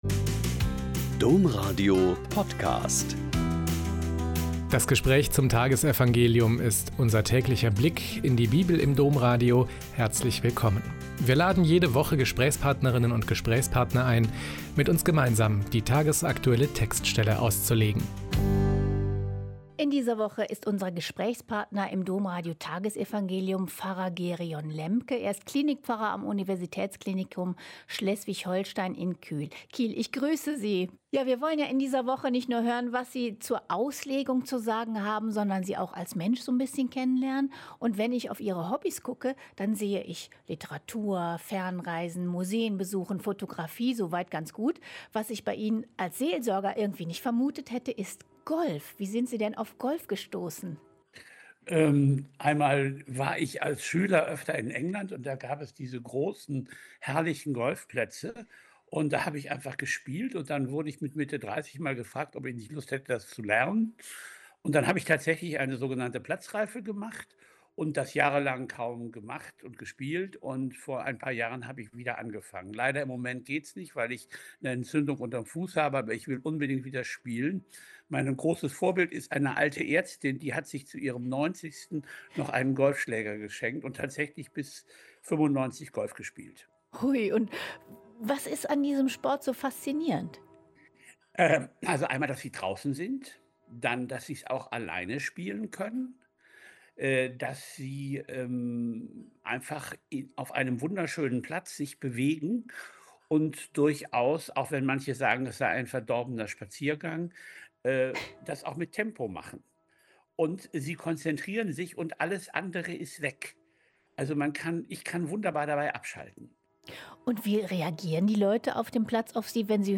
Joh 12,44-50 - Gespräch